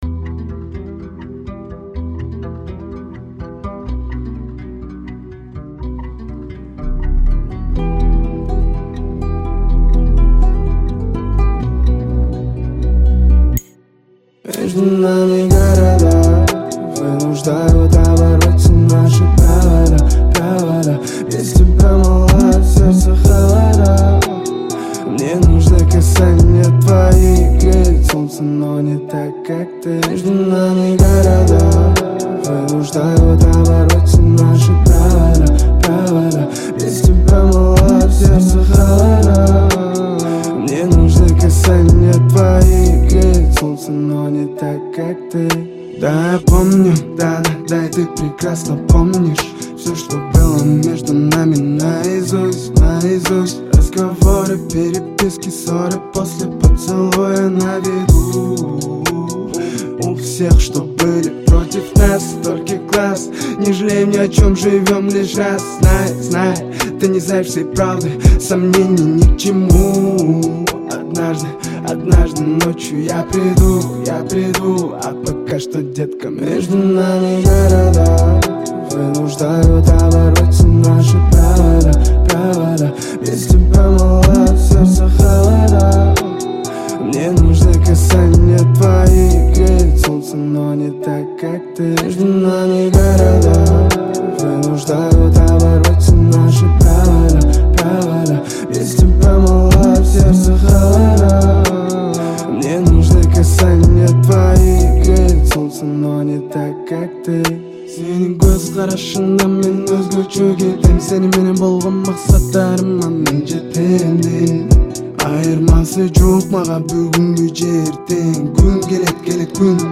• Категория: Кыргызские песни